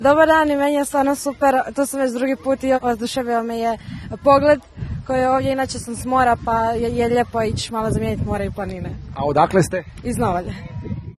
Bili smo na ‘Japu’ i snimili dojmove posjetitelja
A da svi putevi ljeti ne vode samo na more, već i s mora u planine potvrdila je i jedna posjetiteljica s otoka Paga: